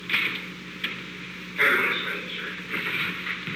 Secret White House Tapes
Conversation No. 908-18
Location: Oval Office
The President met with an unknown man.